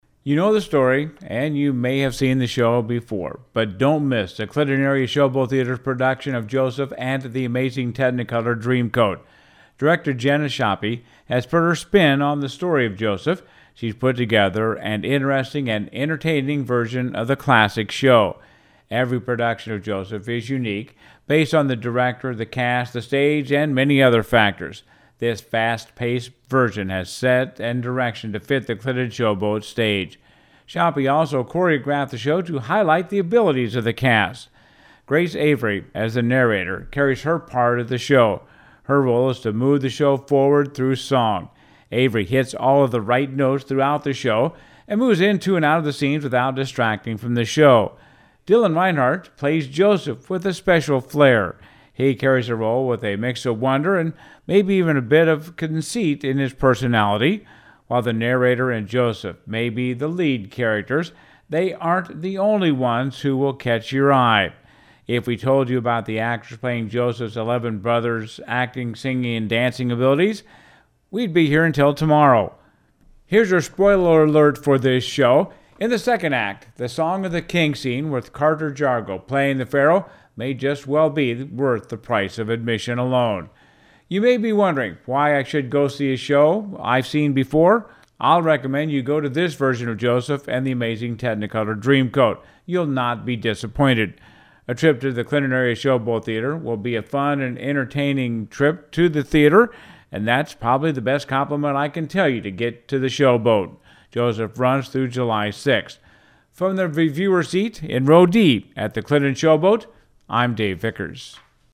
KROS Review of Joseph & The Amazing Technicolor Dreamcoat